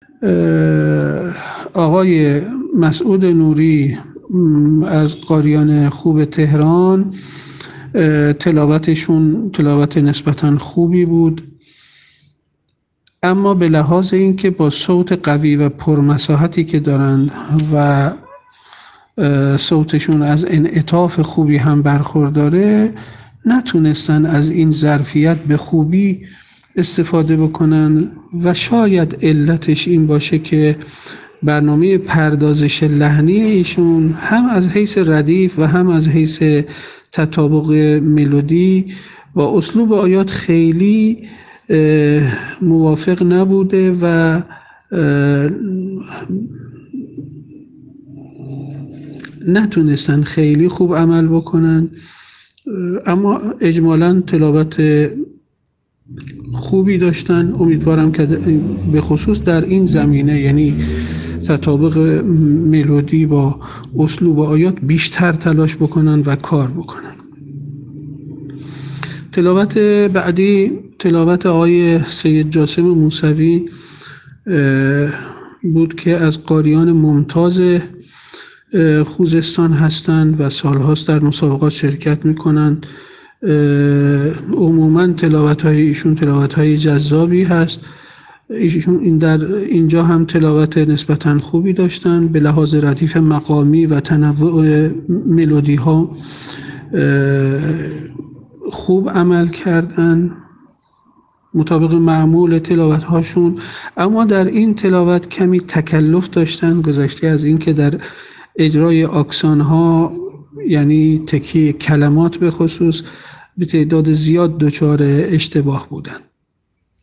فیلم اجرای قاریان فینالیست در دومین شب مسابقات سراسری قرآن
با وجود اینکه ایشان صوت قوی و پرمساحتی دارد و از انعطاف خوبی برخوردار است، اما در تلاوت خود نتوانست از این ظرفیت به خوبی استفاده کند.
شاید علت این باشد که برنامه پردازش لحنی ایشان هم از حیث ردیف و هم از حیث تطابق ملودی و اسلوب آیات خیلی موافق نبود و لذا نتوانست خیلی خوب عمل کند.